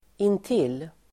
Uttal: [int'il:]